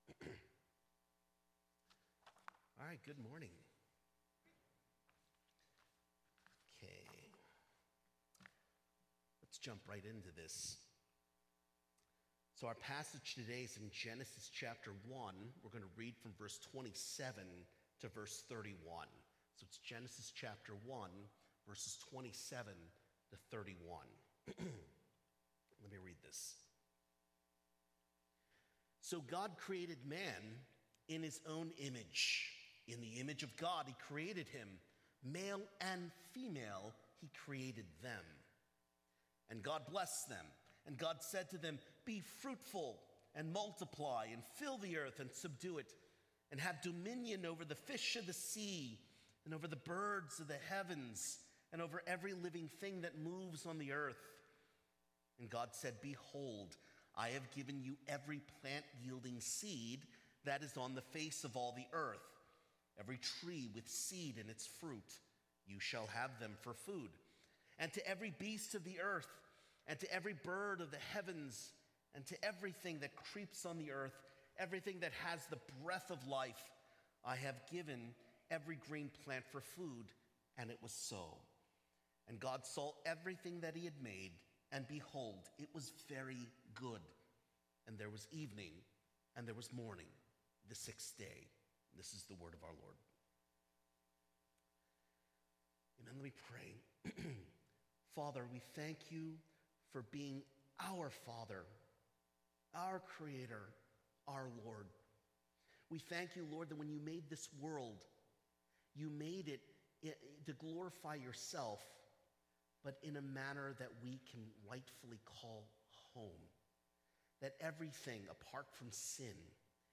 New Hope Presbyterian Church weekly sermons